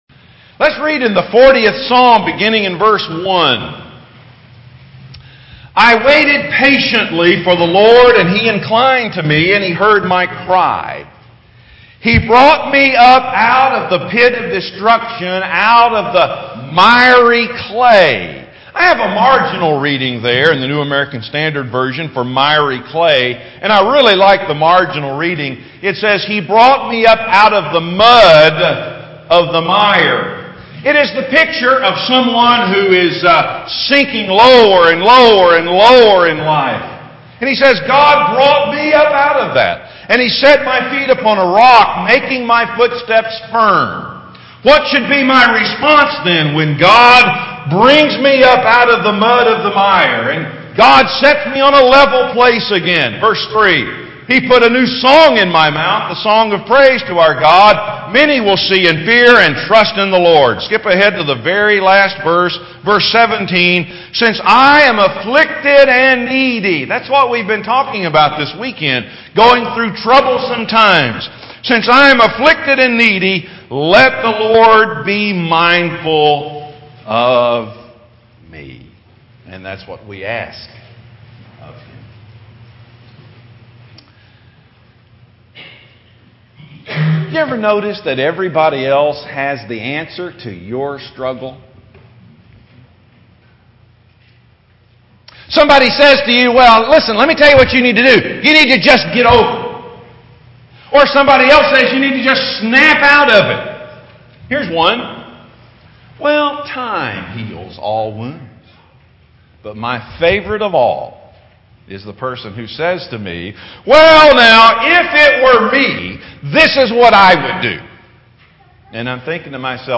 Series: Trusting God in a Troubled World | Gettysburg Bible Forum 2013